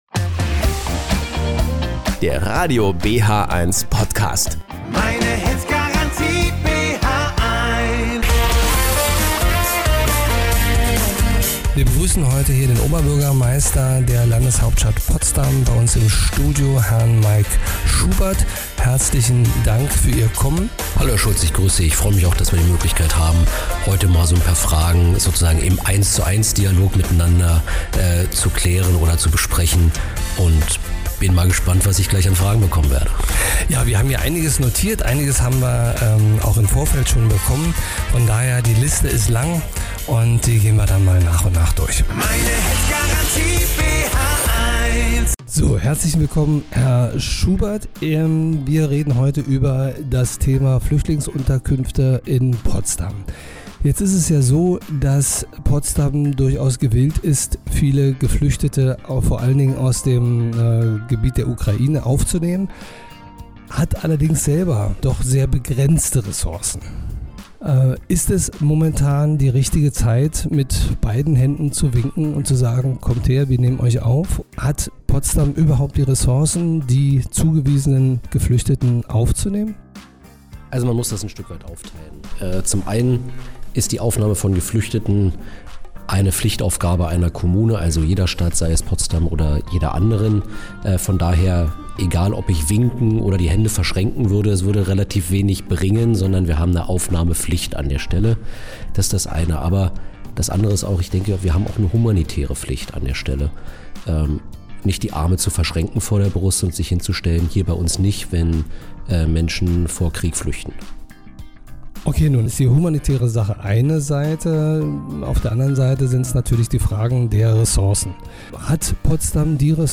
In dieser Folge war der Oberbürgermeister Mike Schubert im Gespräch.